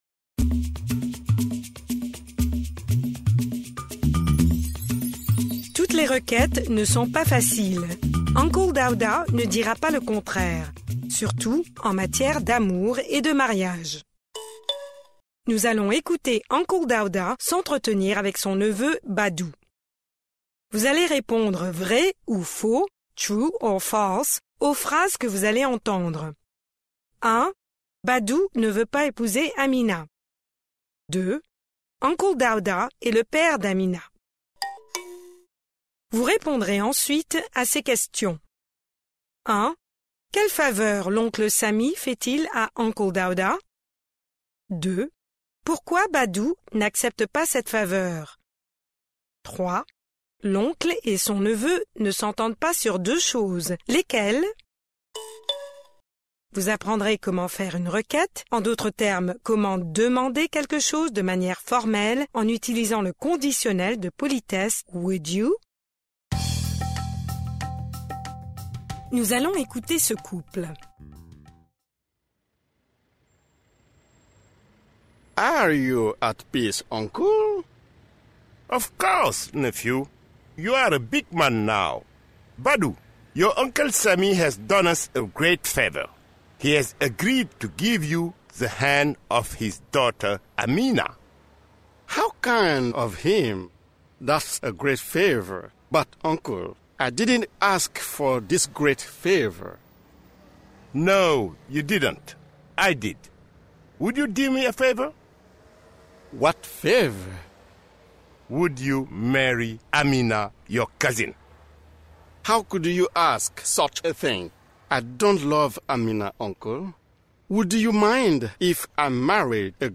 Pre-listening: Nous allons écouter « Uncle Dawda » s’entretenir avec son neveu Badu .